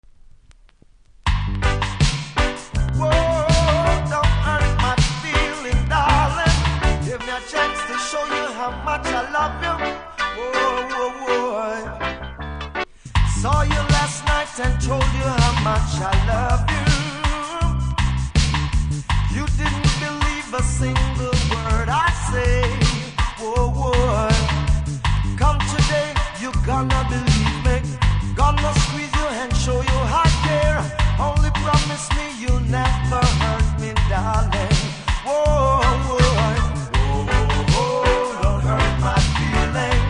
REGGAE 80'S
多少うすキズありますが音は良好なので試聴で確認下さい。